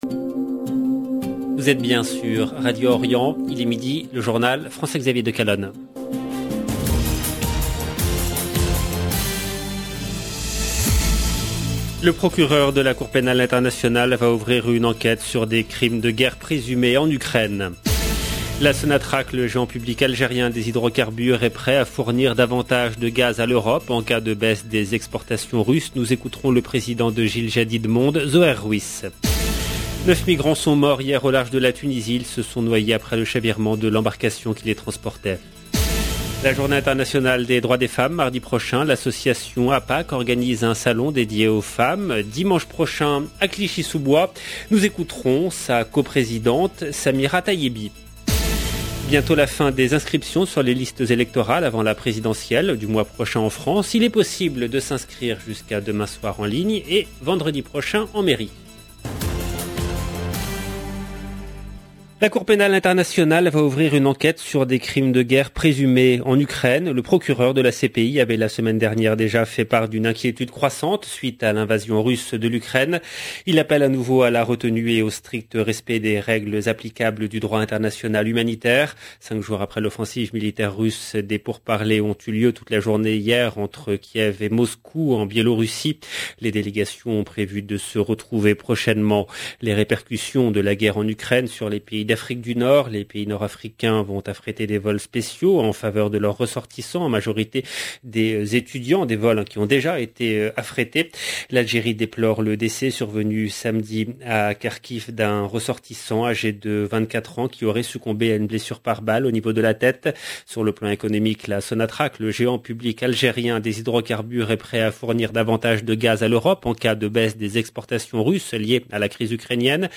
LE JOURNAL DE MIDI EN LANGUE FRANCAISE DU 1/03/22